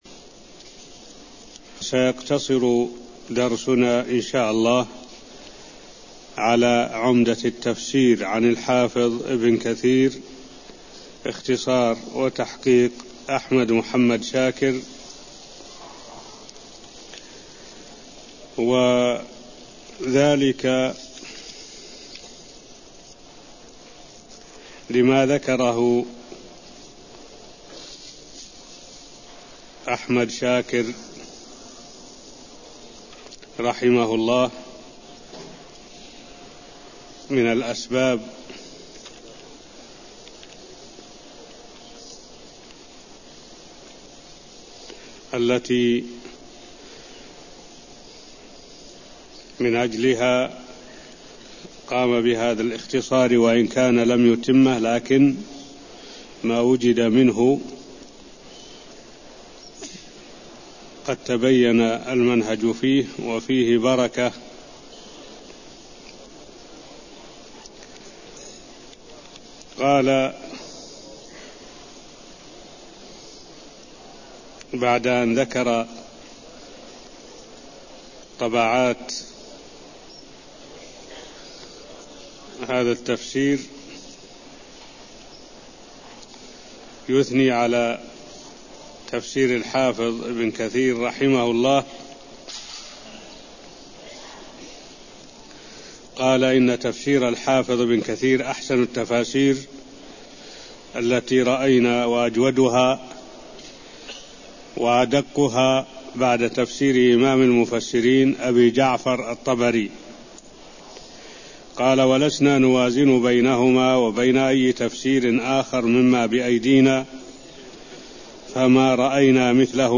المكان: المسجد النبوي الشيخ: معالي الشيخ الدكتور صالح بن عبد الله العبود معالي الشيخ الدكتور صالح بن عبد الله العبود شرح البسملة وفضلها (0009)